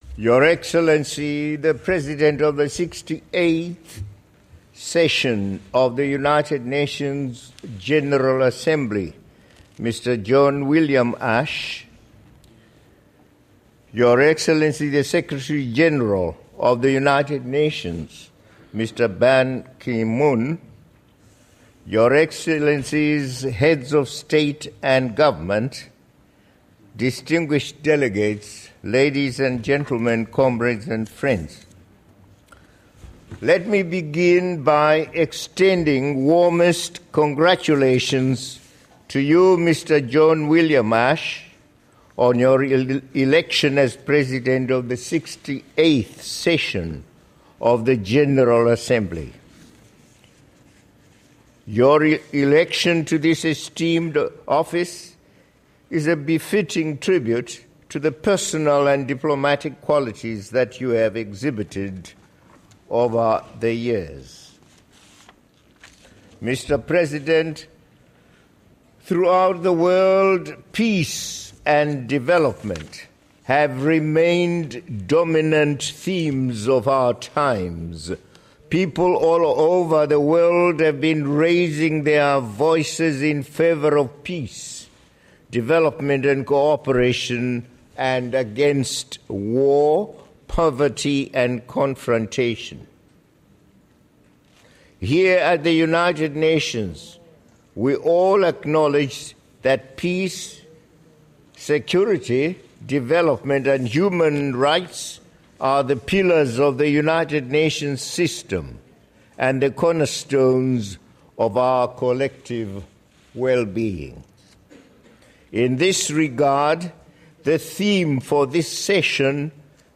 Listen to President Robert Mugabe's UN General Assembly Speech in Full